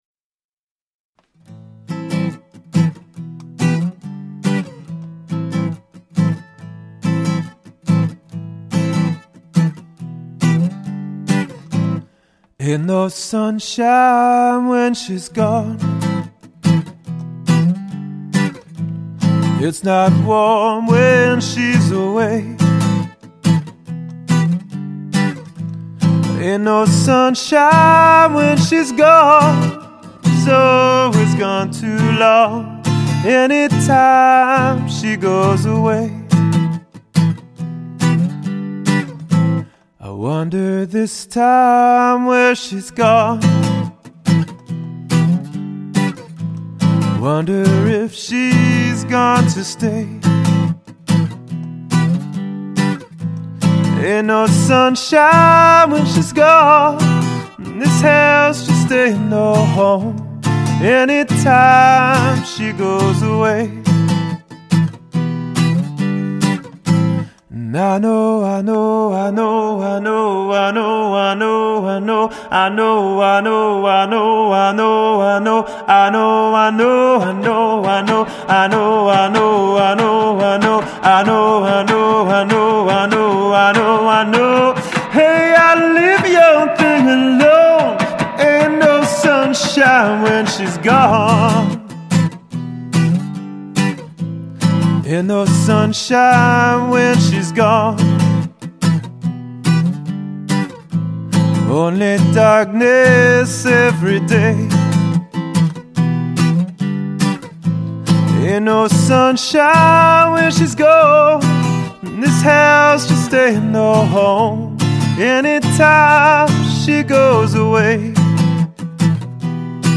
• Unique and mellow voice